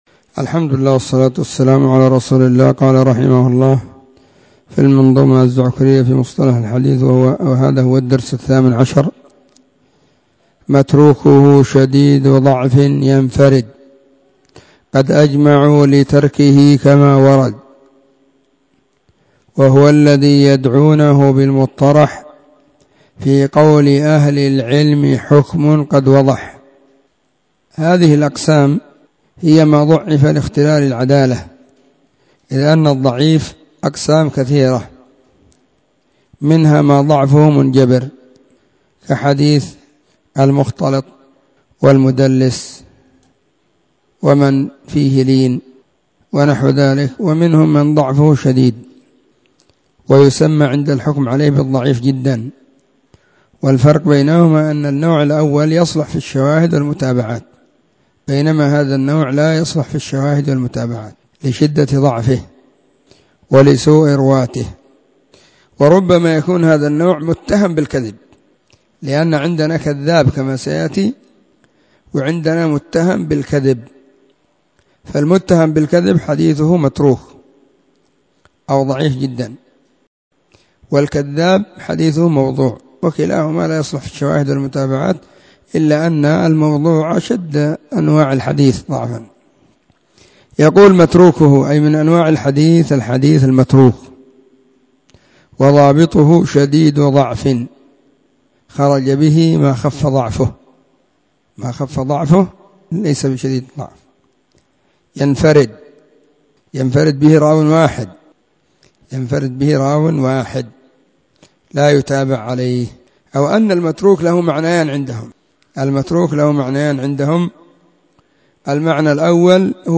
📢 مسجد الصحابة – بالغيضة – المهرة، اليمن حرسها الله.
🔻 الدرس :18